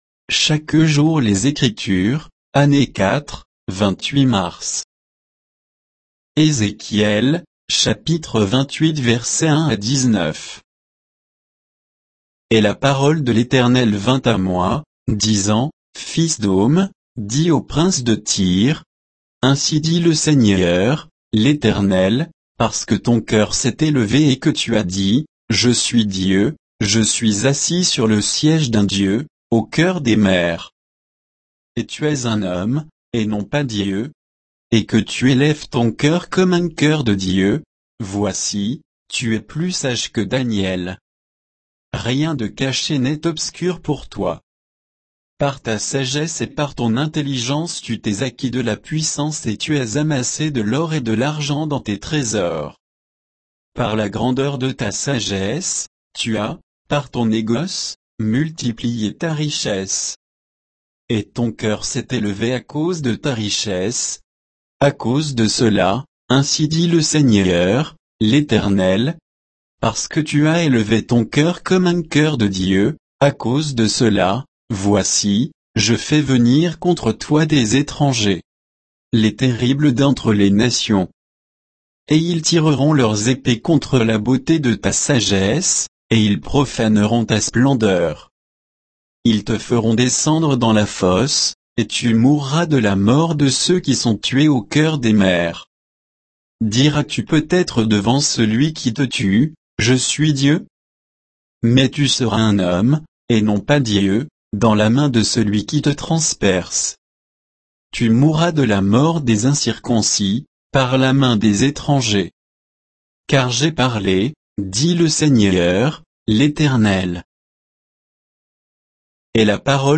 Méditation quoditienne de Chaque jour les Écritures sur Ézéchiel 28